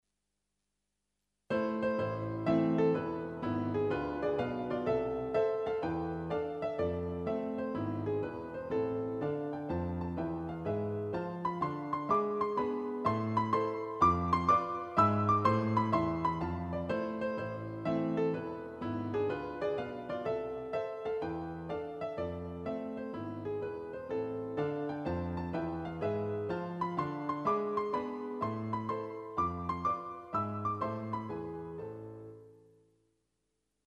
Heaven　作品番号4-6 ver4 (0:34)、Op4-5の速度3倍。